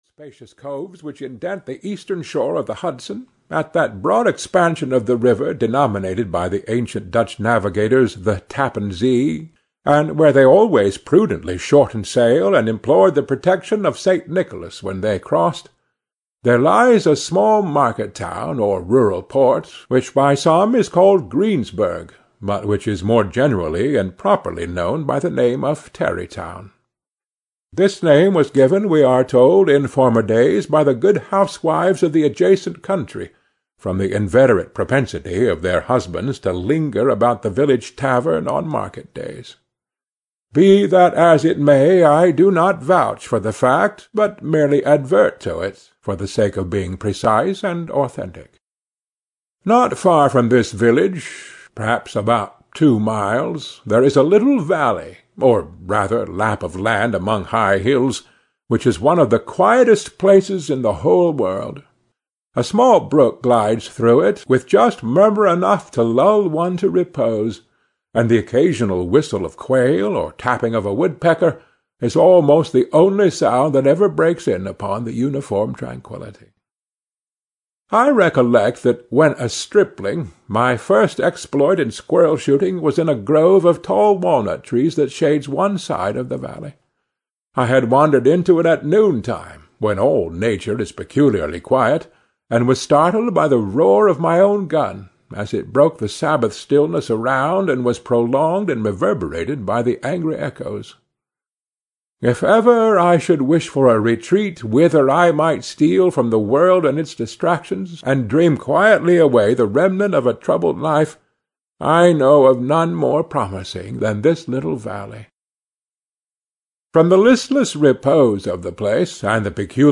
The Legend of Sleepy Hollow (EN) audiokniha
Ukázka z knihy